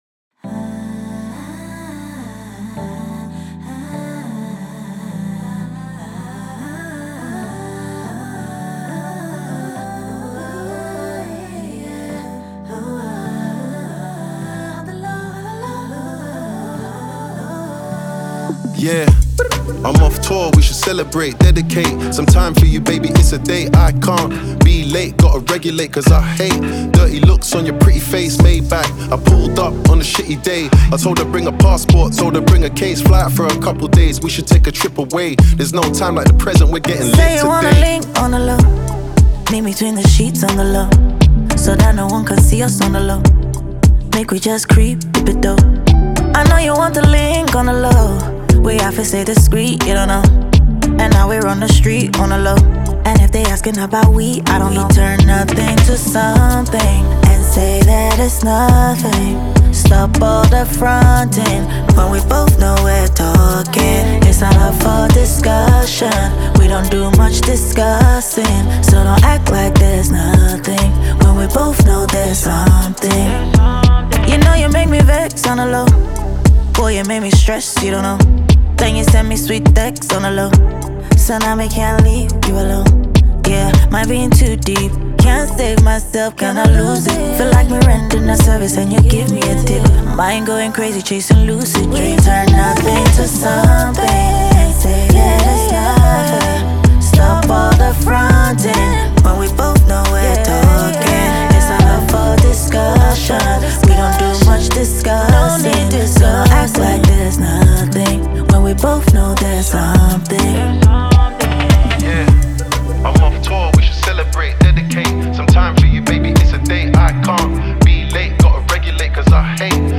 known for his lyrical precision and laid-back swagger